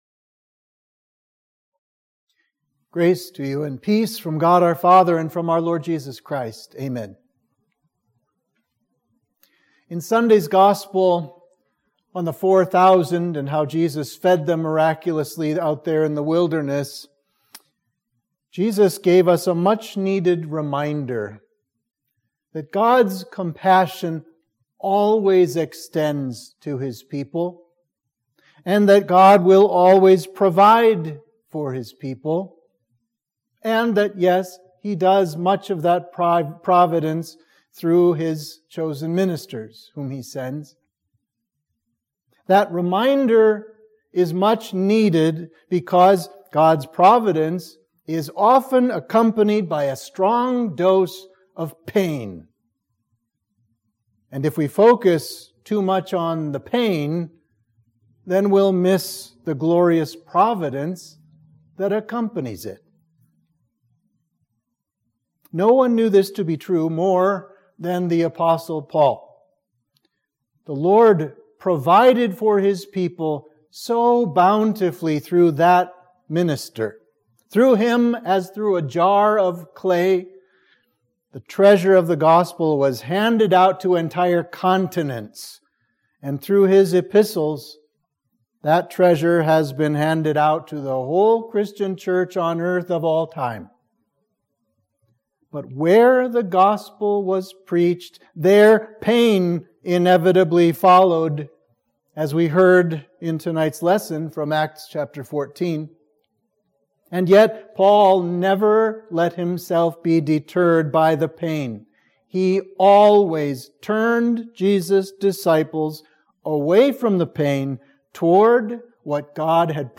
Sermon for midweek of Trinity 7